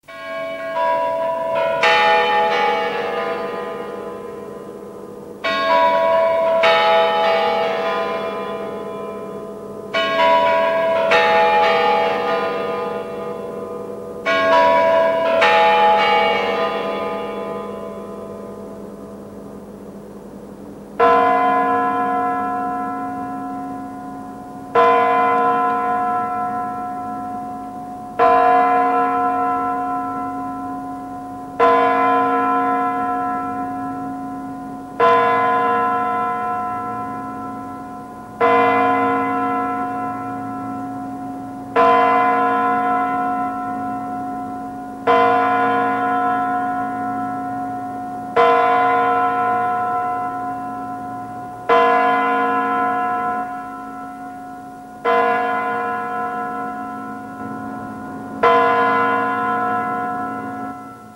Boy_kur.ogg  (размер файла: 1,13 Мб, MIME-тип: application/ogg ) бой Курантов История файла Нажмите на дату/время, чтобы просмотреть, как тогда выглядел файл.